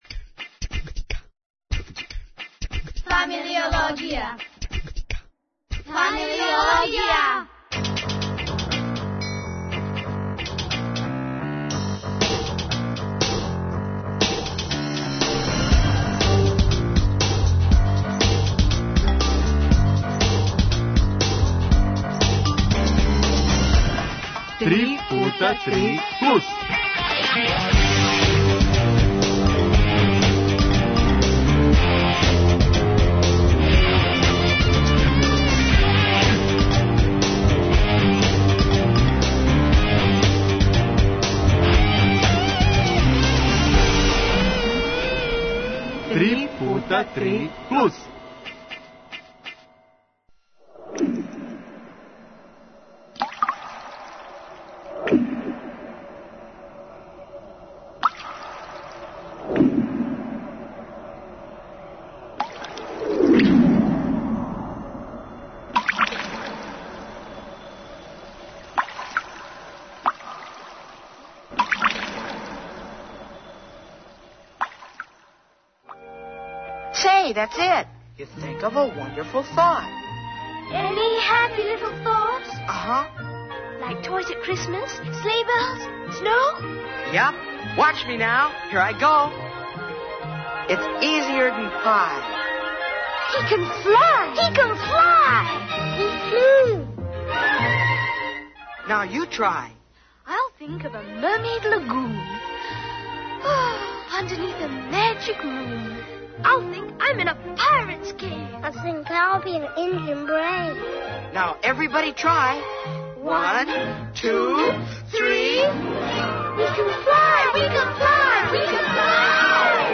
Укључење из Зоо врта поводом Светског дана птица...